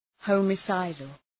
Προφορά
{,hɒmı’saıdəl}